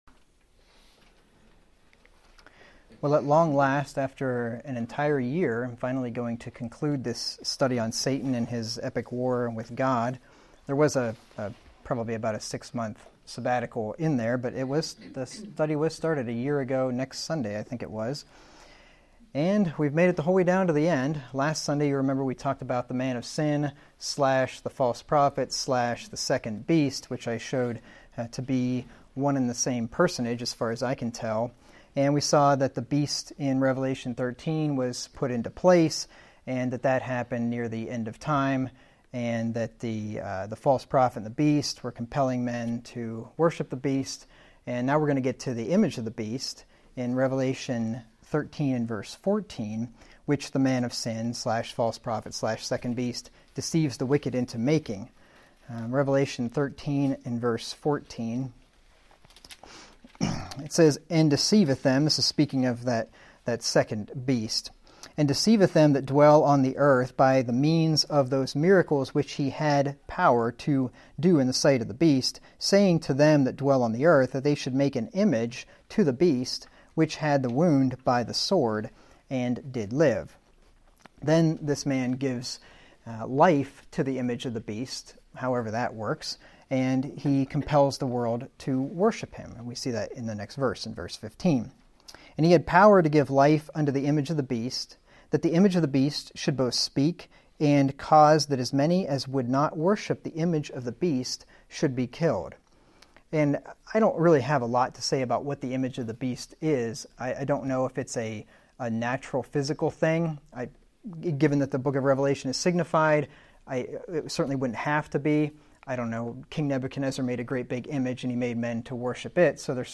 Sermons by 2019 | The Excelsior Springs Church